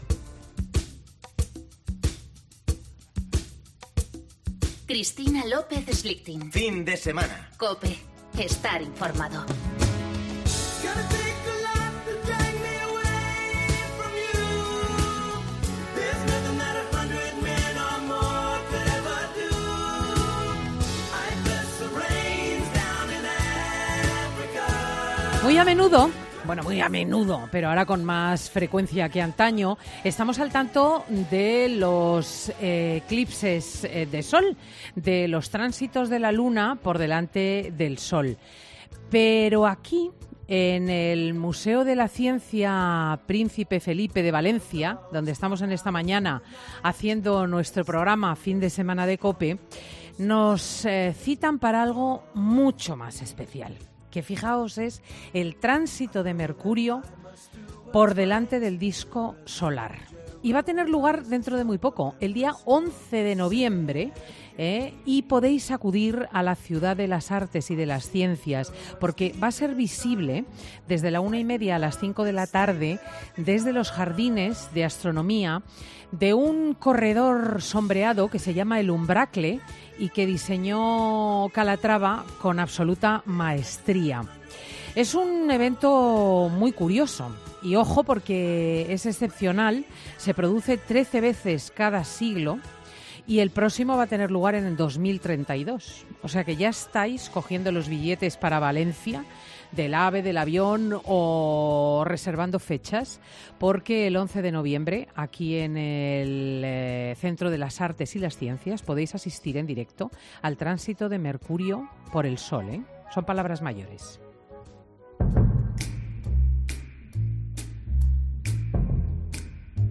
Dos muejres taxistas en Valencia cuentan la seguridad que sienten ahora cada vez que recogen a un pasajero